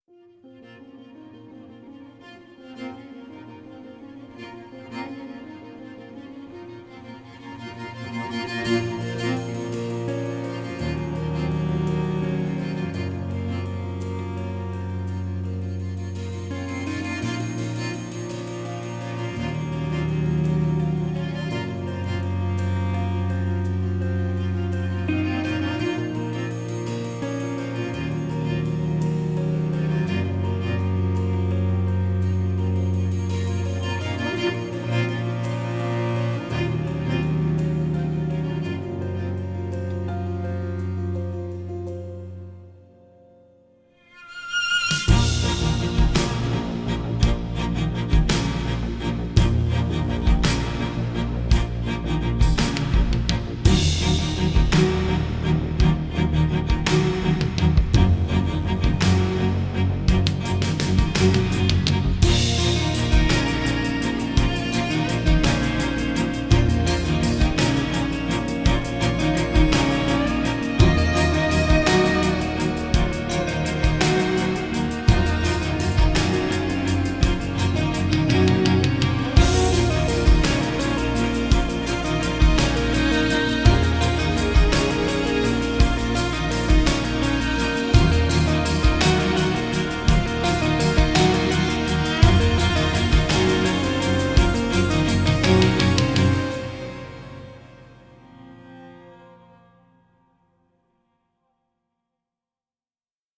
tema dizi müziği, duygusal hüzünlü heyecan fon müziği.